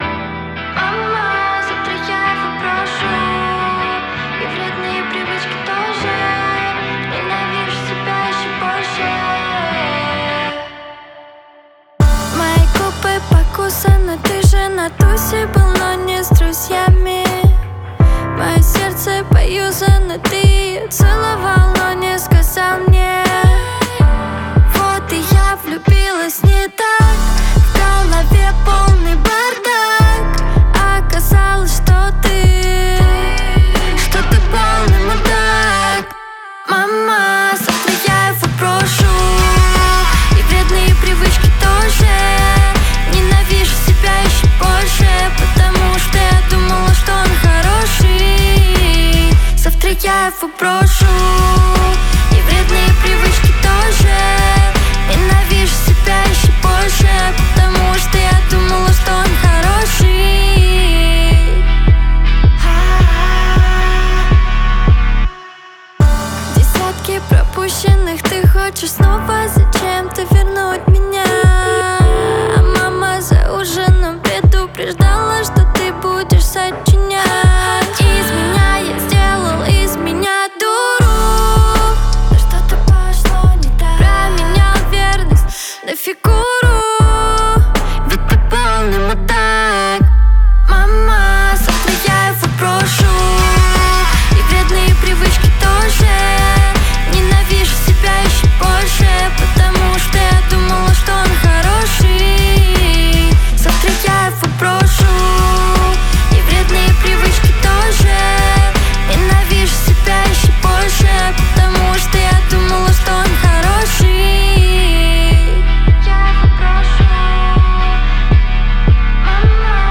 современная поп-песня